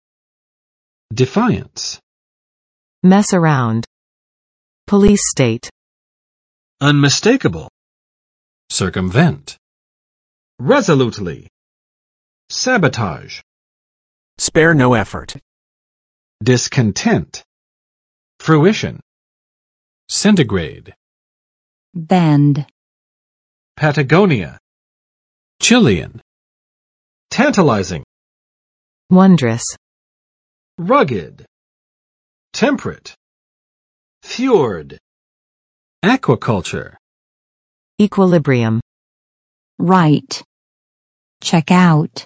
[dɪˋfaɪəns] n. 反抗